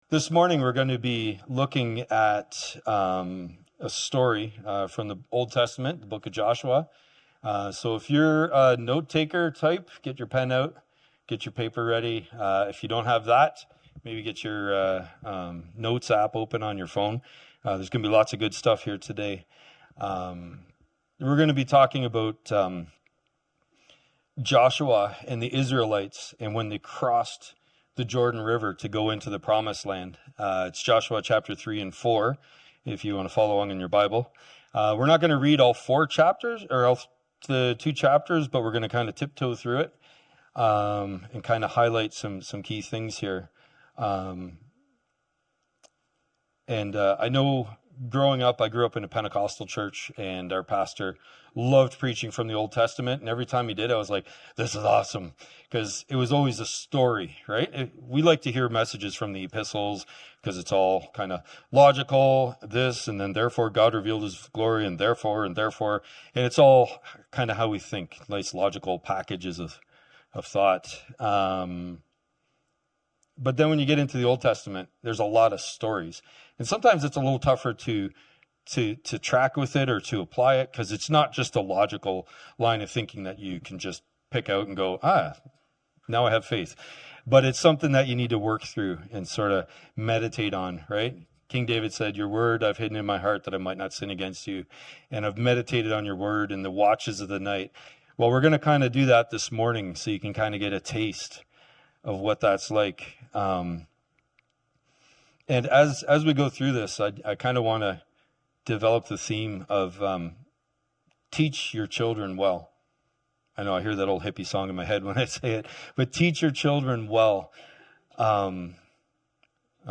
From Series: "Guest Preachers"